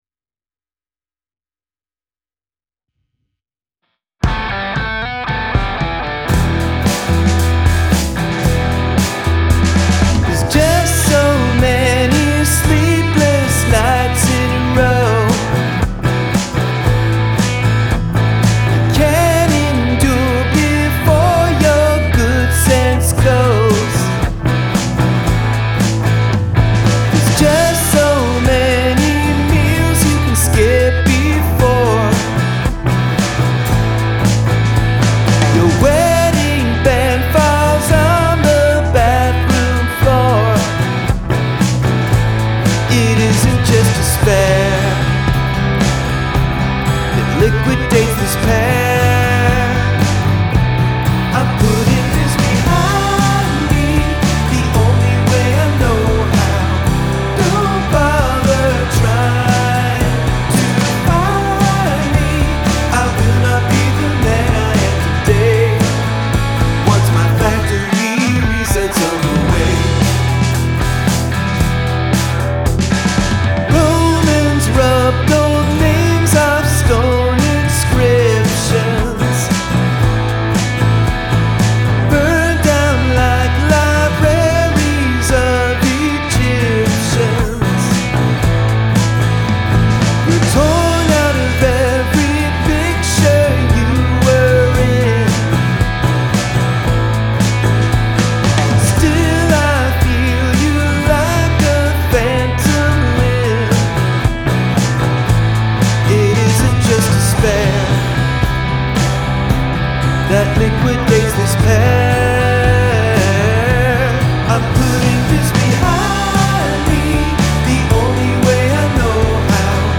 Must include at least one mood shift (musical & lyrical)